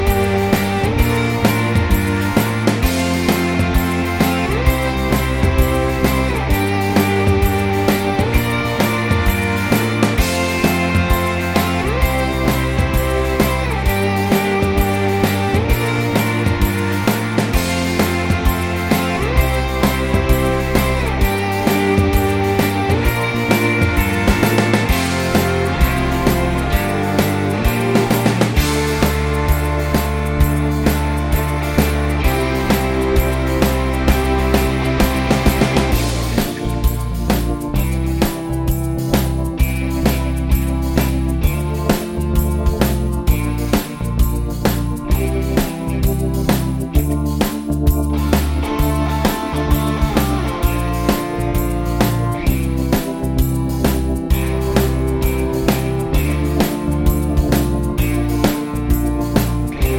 no Backing Vocals Indie / Alternative 3:46 Buy £1.50